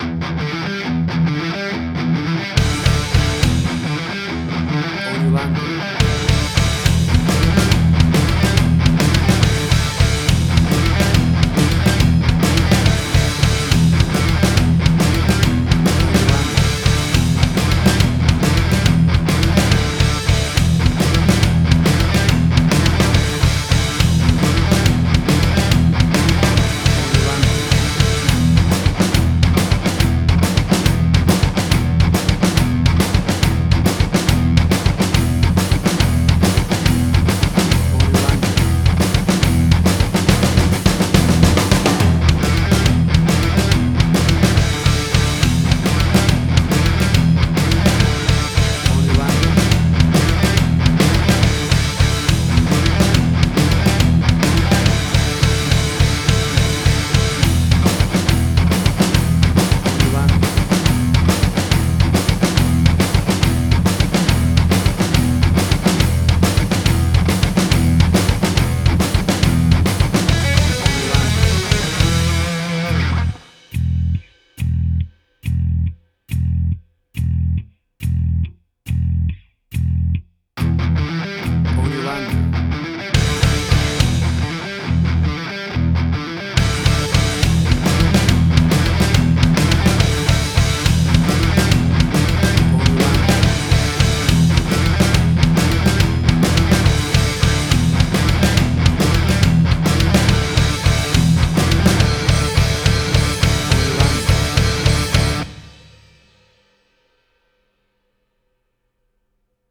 Hard Rock
Heavy Metal.
WAV Sample Rate: 16-Bit stereo, 44.1 kHz
Tempo (BPM): 139